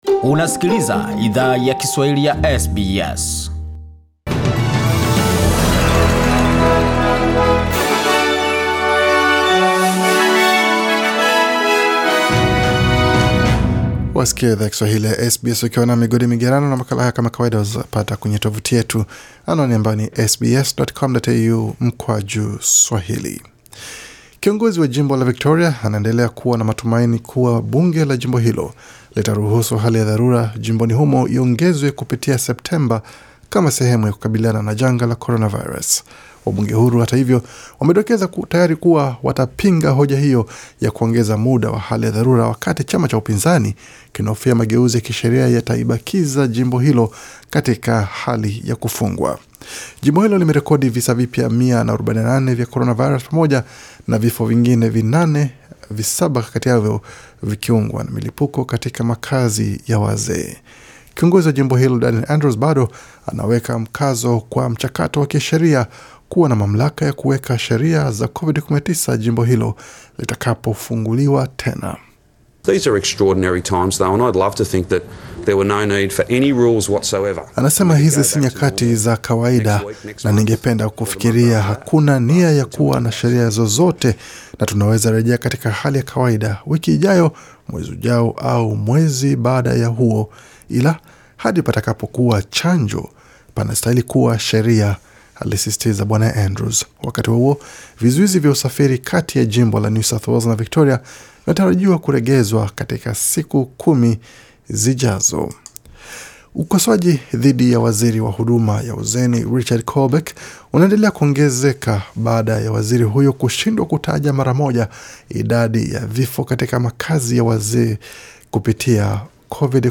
Taarifa ya habari 25 Agosti 2020